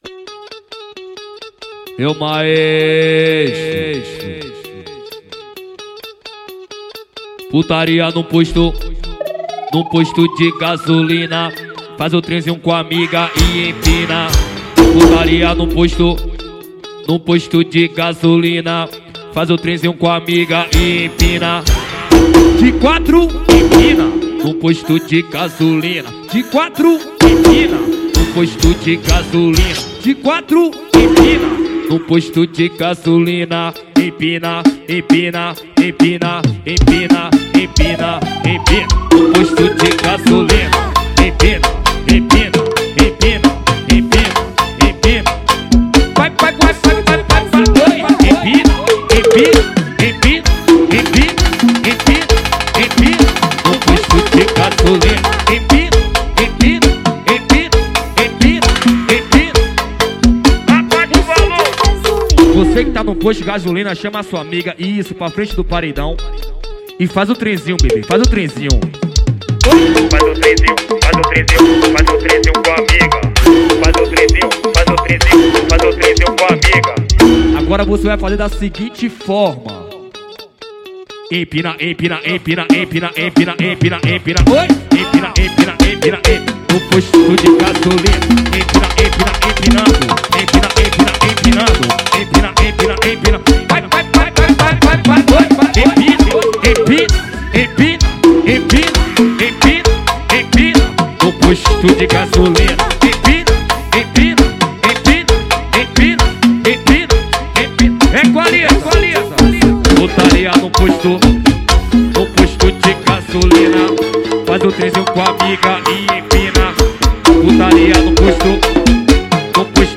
2024-06-16 20:17:08 Gênero: MPB Views